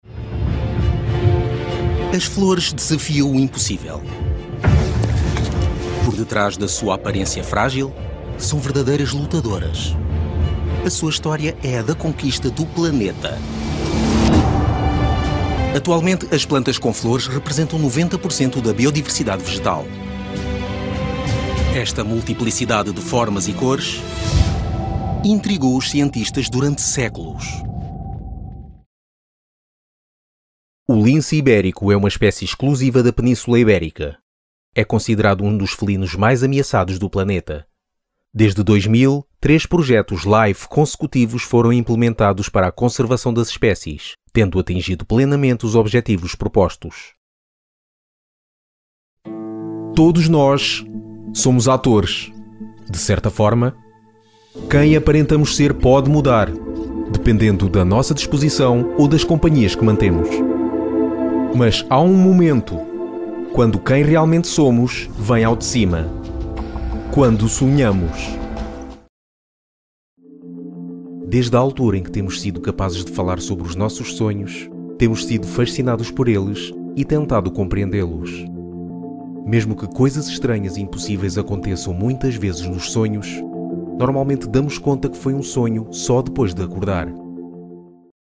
Documentaries
Also, I can do many voice tones: calm, energetic, friendly, aggressive, informative, funny, and many more.
If you want a versatile Portuguese male voice, contact me.
Microphone: Rode NT-1A large diaphragm